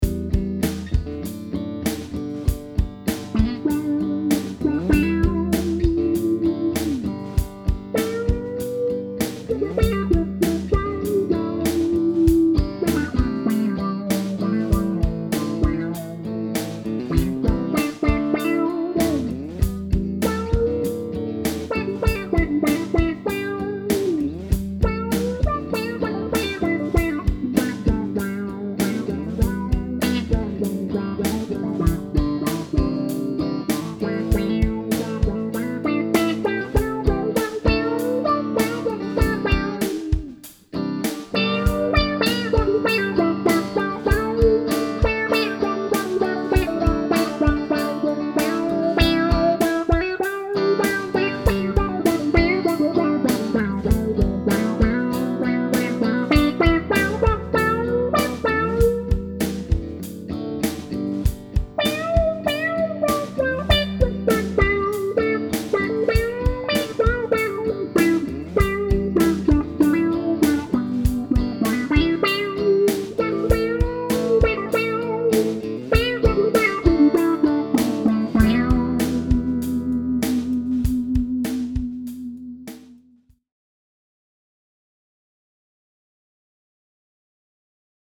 The following clips were all played with my Fender American Deluxe Strat into my trusty Roland Cube 60, set to a clean “Blackface” setting.
First off, is a little ditty that I put together with a clean rhythm track, and doing a lead over it.